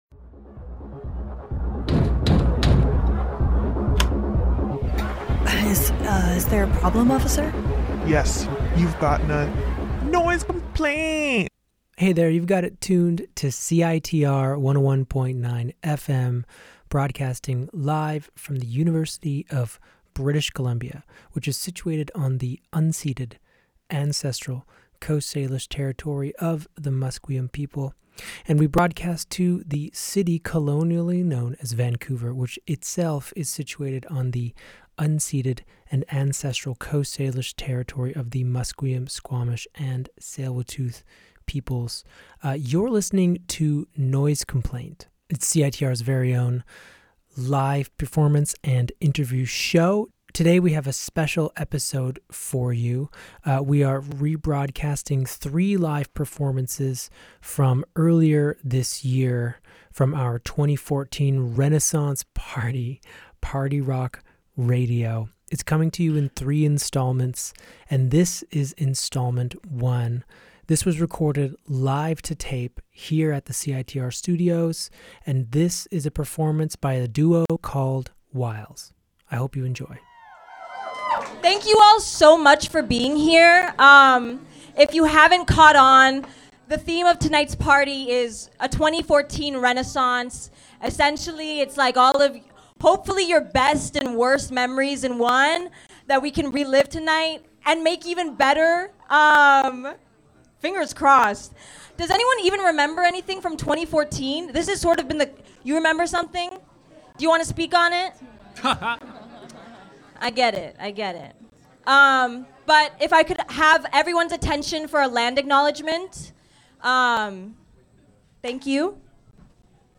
A live session recorded directly to tape!